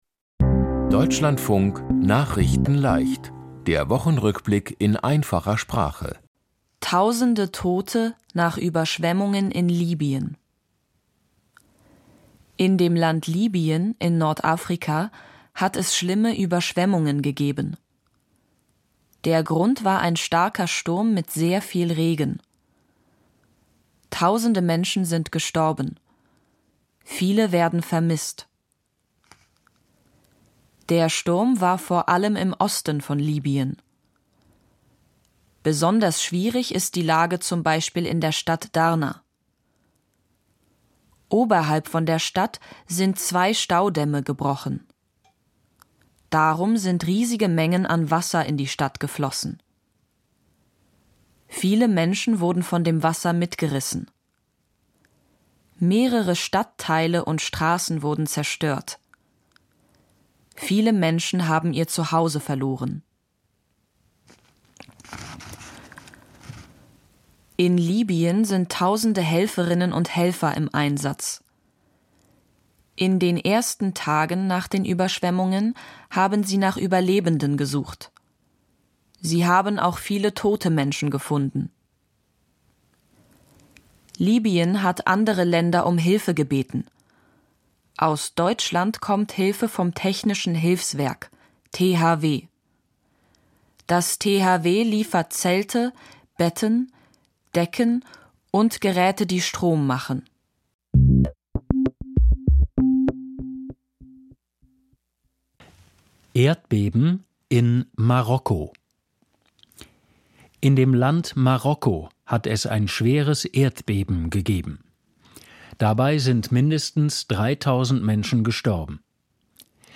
Die Themen diese Woche: Tausende Tote nach Überschwemmungen in Libyen, Erd-Beben in Marokko, Russland und Nord-Korea wollen zusammen-arbeiten, Vor einem Jahr: Beginn der Proteste im Iran, Bild von Van Gogh wieder da und Deutsche Basket-Baller sind Welt-Meister. nachrichtenleicht - der Wochenrückblick in einfacher Sprache.